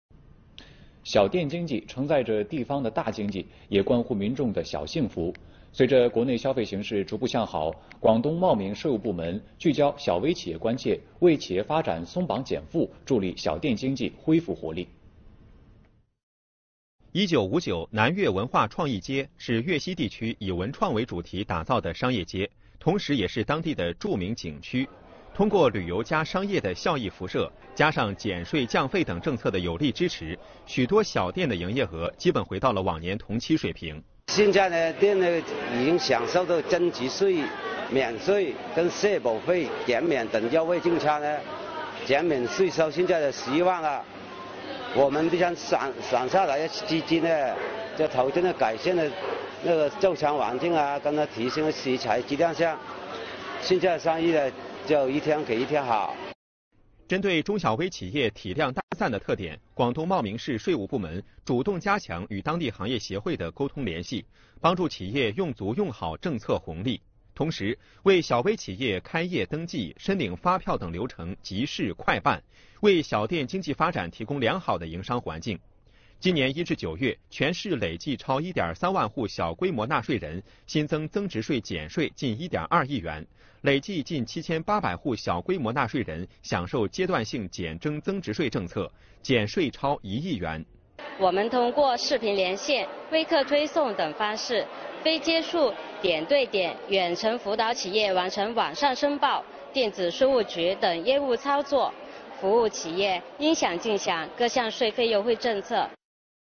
视频来源：央视《正点财经》